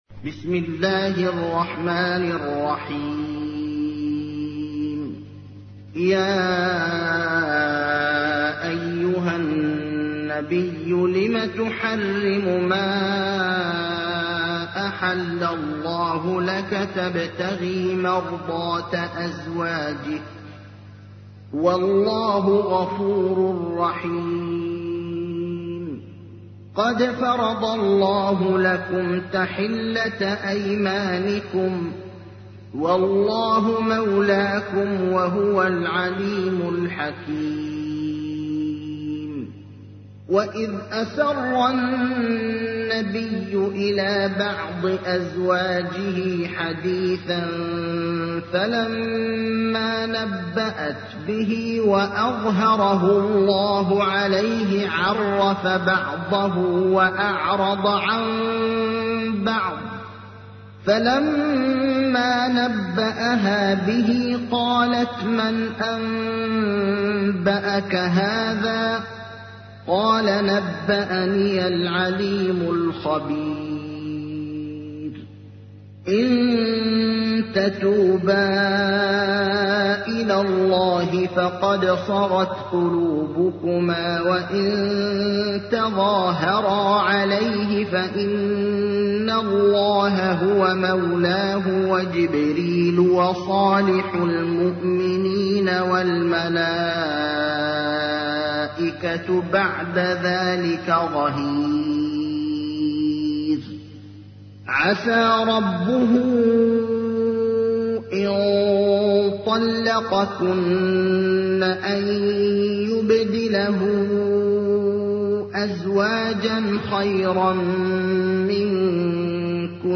تحميل : 66. سورة التحريم / القارئ ابراهيم الأخضر / القرآن الكريم / موقع يا حسين